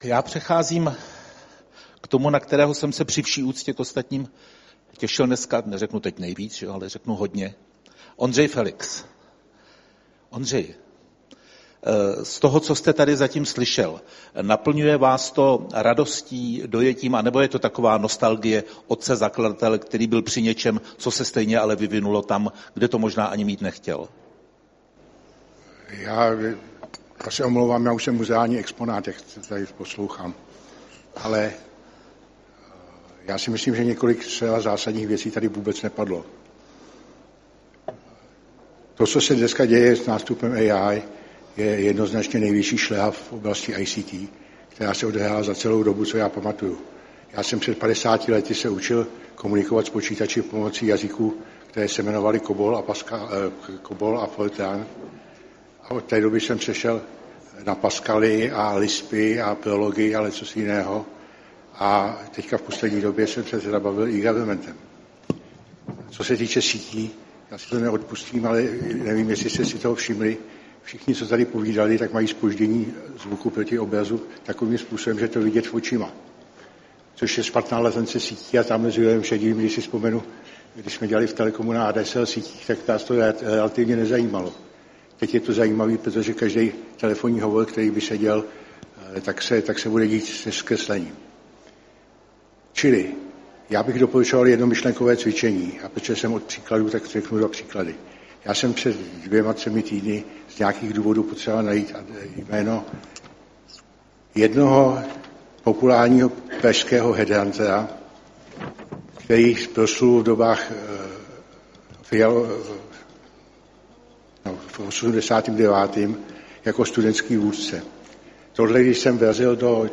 Z konference ISSS
Projev Ondřeje Felixe